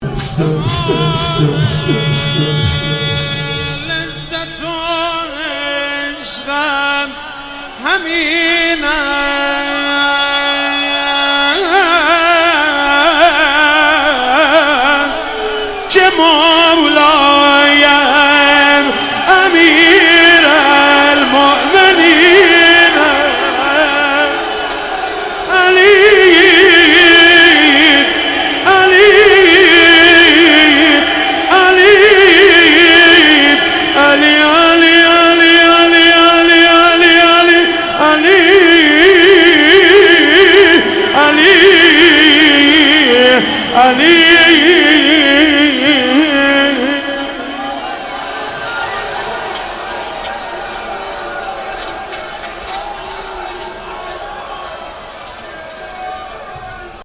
شهادت امام صادق علیه السلام